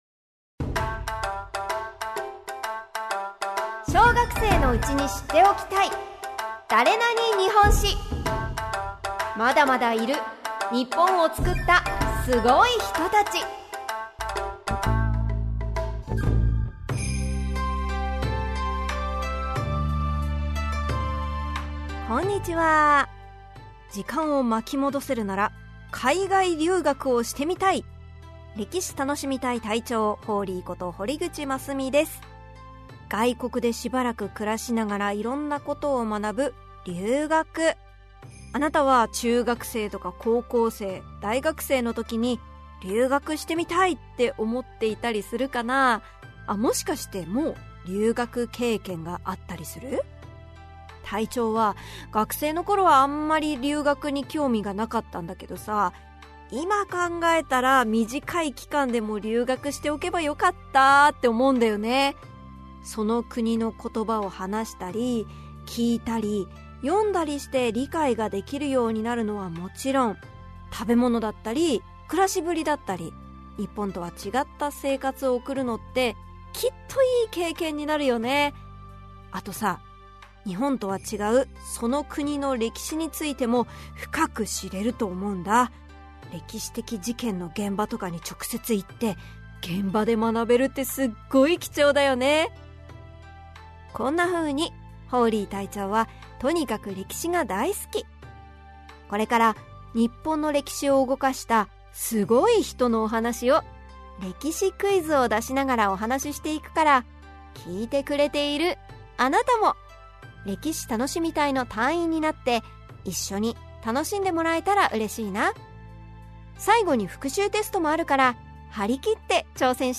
[オーディオブック] 小学生のうちに知っておきたい！だれなに？日本史 Vol.12 〜津田梅子〜